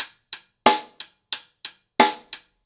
Wireless-90BPM.11.wav